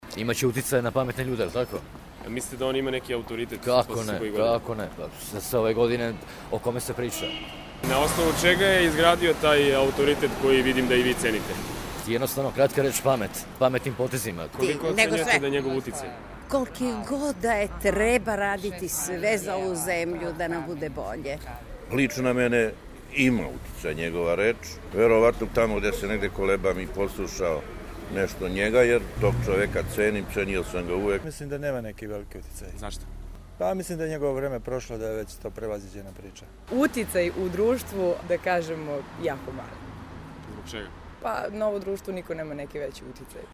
Beograđani o Ćosiću